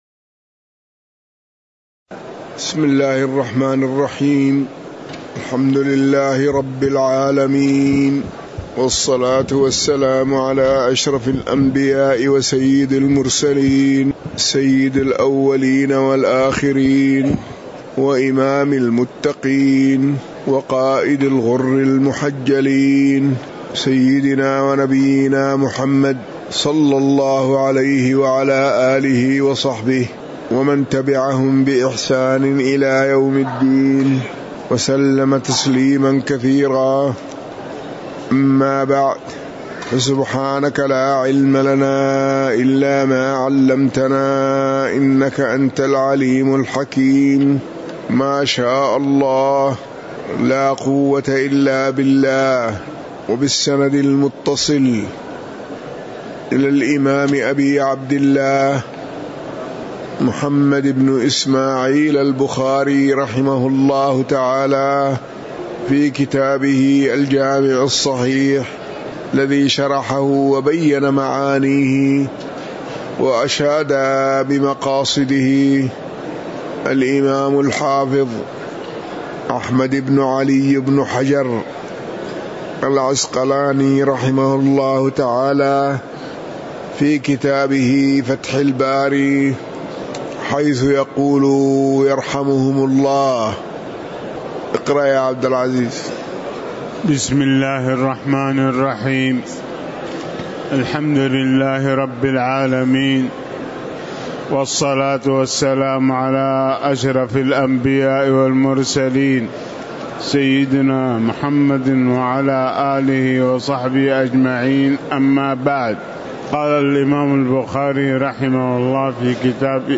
تاريخ النشر ١٨ محرم ١٤٤١ هـ المكان: المسجد النبوي الشيخ